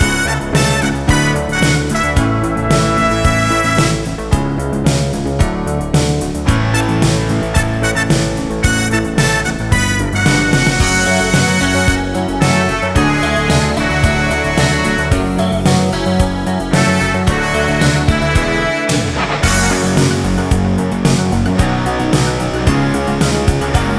Soundtracks 3:26 Buy £1.50